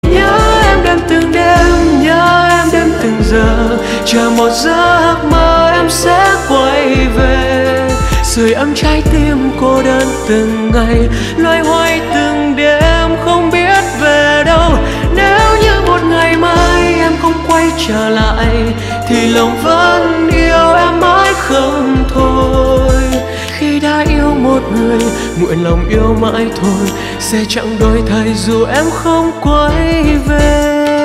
Nhạc Chuông Nhạc Trẻ